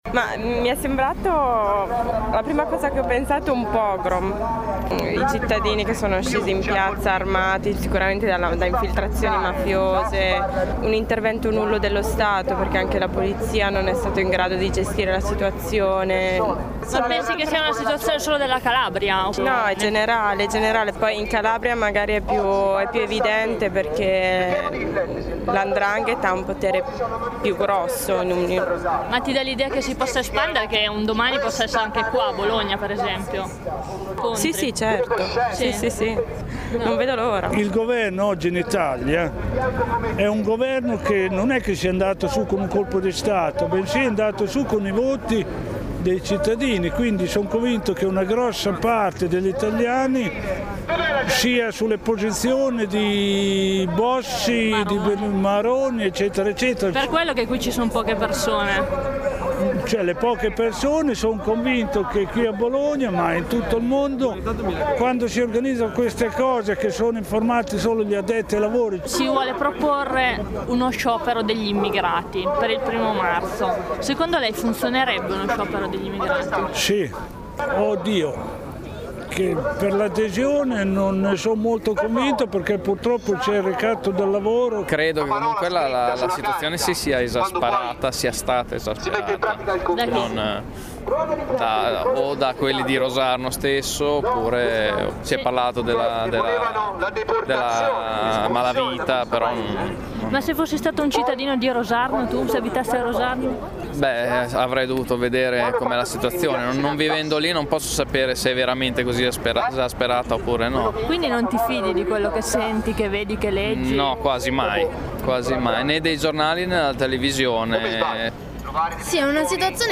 A Bologna, lunedì pomeriggio, c’è stato un presidio di solidarietà con i cittadini stranieri protagonisti degli scontri di questi giorni a Rosarno. E’ stato organizzato dall’A3F, Associazione 3 Febbraio, che ha invitato immigrati e associazioni a partecipare.